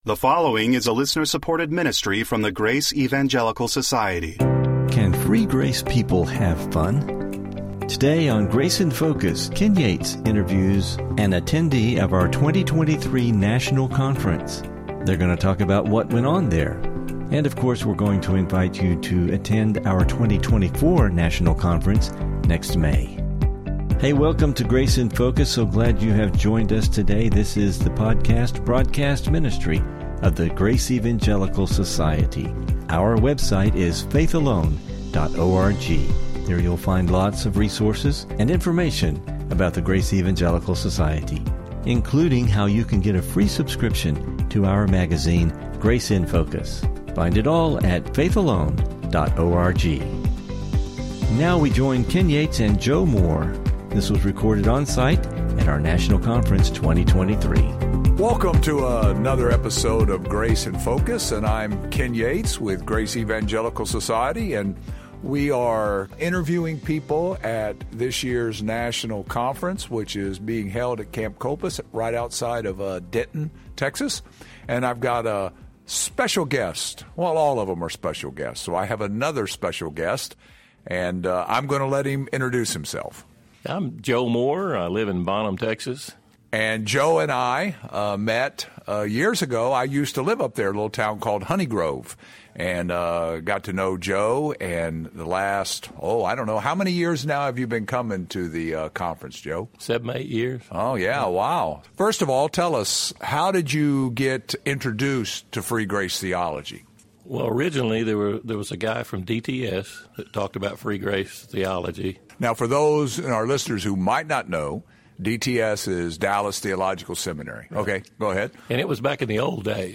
Welcome to Grace in Focus radio / podcast.